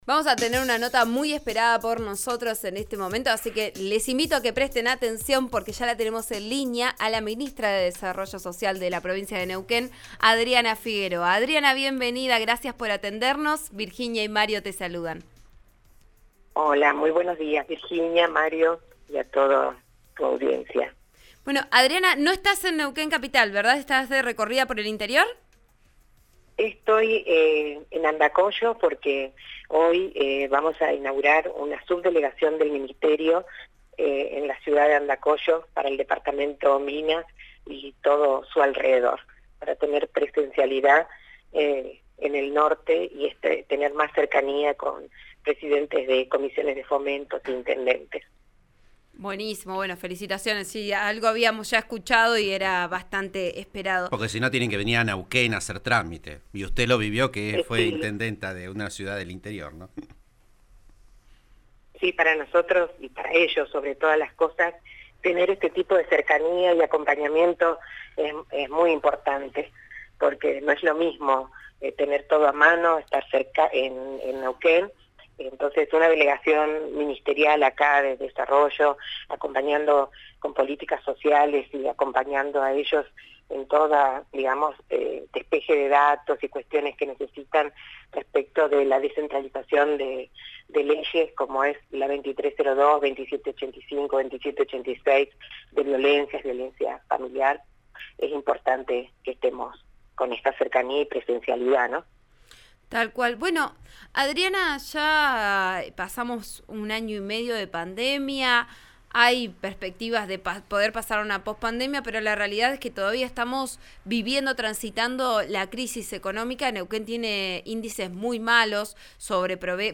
Desde Andacollo, donde se inauguraba una subdelegación del Ministerio, Figueroa dialogó con «Vos A Diario» (RN RADIO 89.3) sobre las estrategias que se emprendieron en estos tiempos .